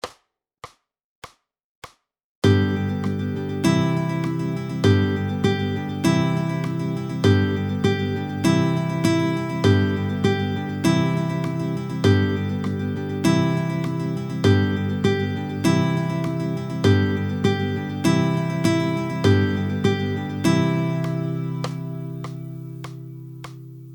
√ برای ساز گیتار | سطح آسان